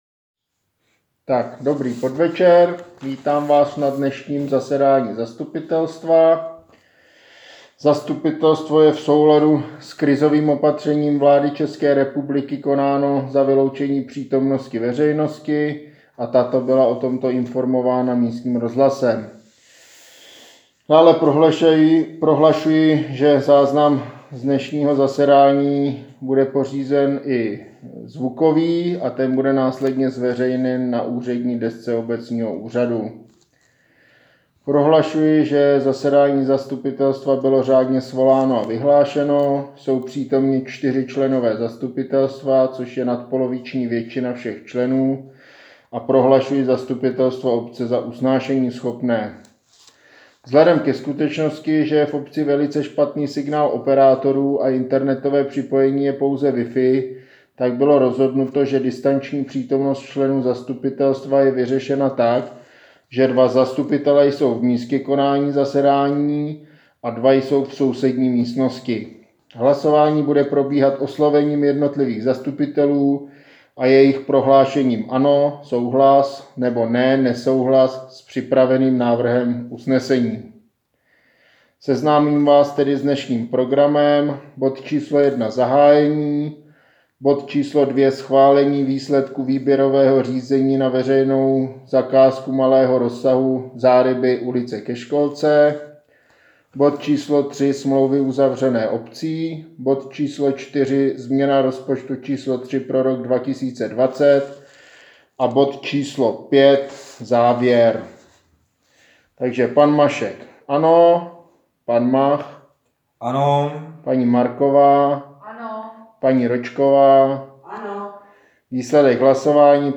Zvukový záznam ze zasedání zastupitelstva obce dne 01.04.2020